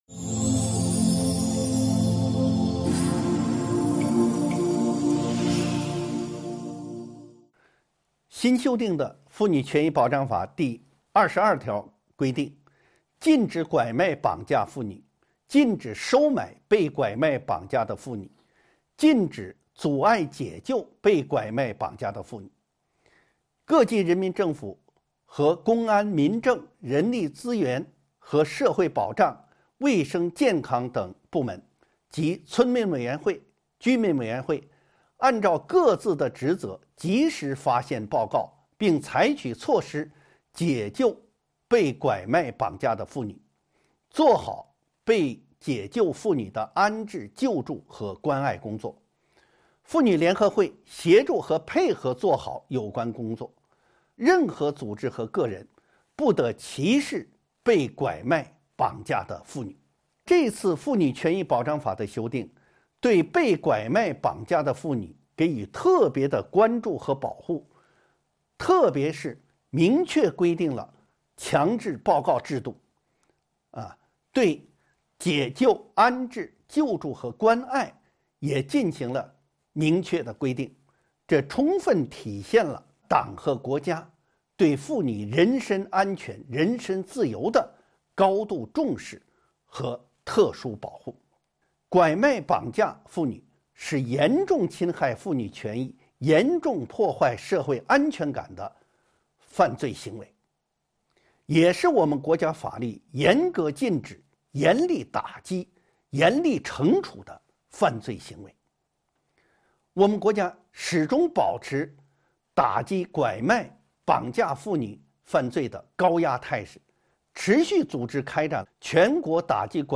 音频微课：《中华人民共和国妇女权益保障法》13.解救、安置、救助、关爱被拐卖、绑架的妇女